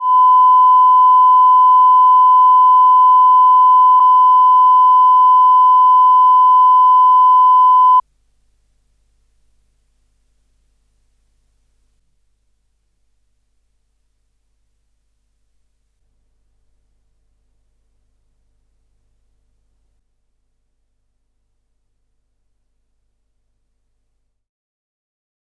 Anyway, this is my "hiss" track:
5sec - 1kHz / +4dBu signal to all tracks from generator and all tracks REPRO mode. Calibration 250nW/m.
5sec - 1kHz / +4dBu signal to all tracks from generator and all tracks REPRO mode. Calibration +4dB over 250nW/m.
5sec - Generator is switched off but still recording silence and all tracks REPRO mode. Calibration 250nW/m.
5sec - Generator is switched off but still recording silence and all tracks REPRO mode. Calibration +4dB over 250nW/m.
5sec - Virgin, blank tape, all tracks REPRO mode. Calibration 250nW/m.
5sec - Virgin, blank tape, all tracks REPRO mode. Calibration +4dB over 250nW/m.
(Sorry for sine quality and "ticking" noise, it is from the generator from the mix console.)